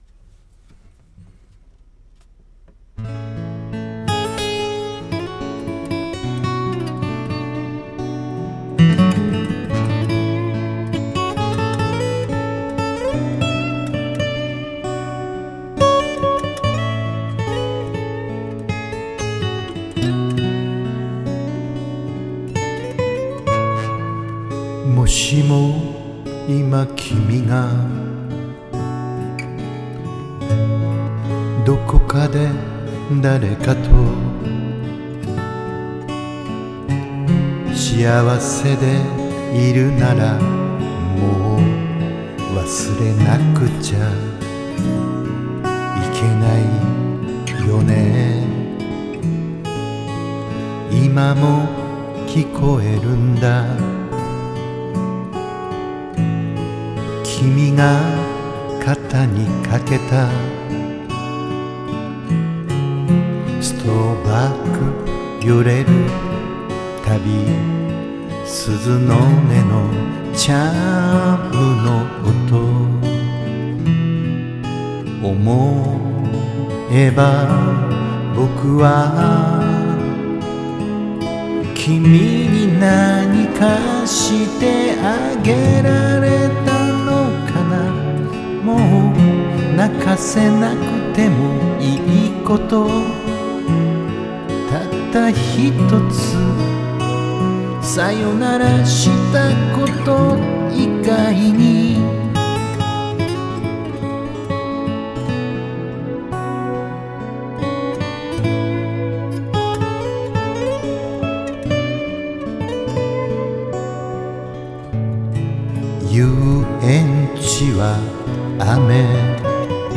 カバー曲　　青春時代の曲です
悲しく、切なく、あの頃の時代を思い出します    D-45REとアダマス